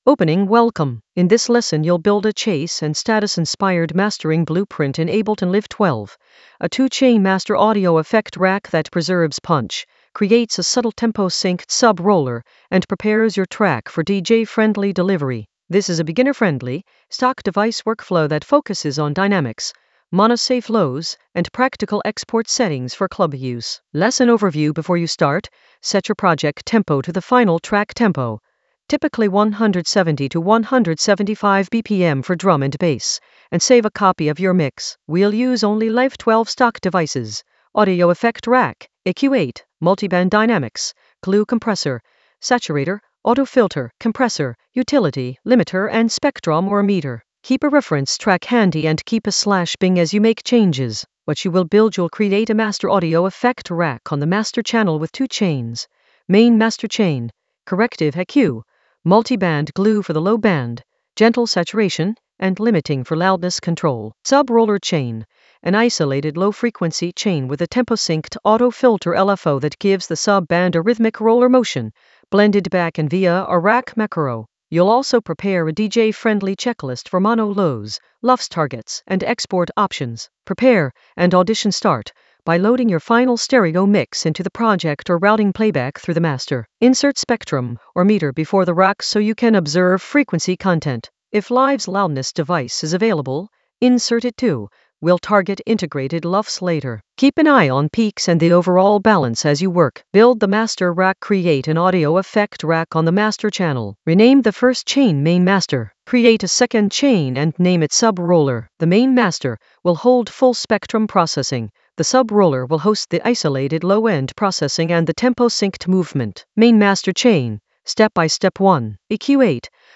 An AI-generated beginner Ableton lesson focused on Chase & Status Ableton Live 12 subweight roller blueprint with DJ-friendly structure in the Mastering area of drum and bass production.
Narrated lesson audio
The voice track includes the tutorial plus extra teacher commentary.